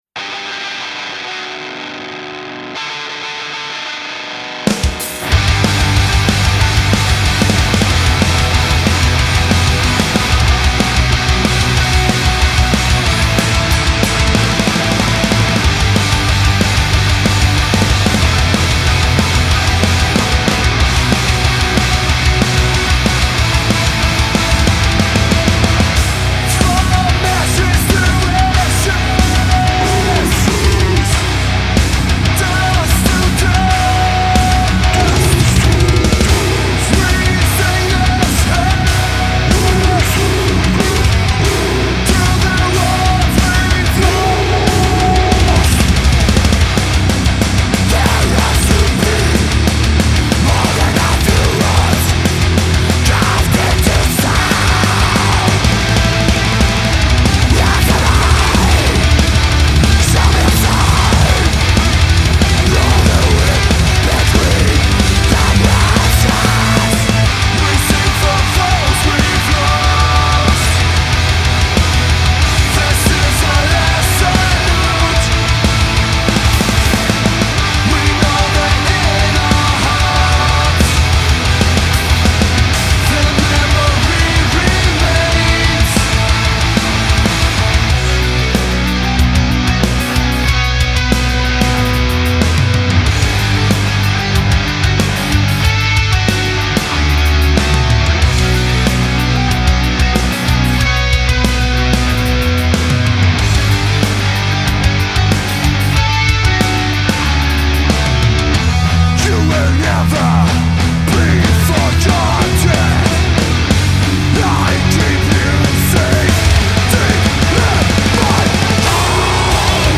Modern metal, Soldano, Recto, Krank....
I would like the vocals a tad brighter and the snare more glued to the mix...nothing else to say.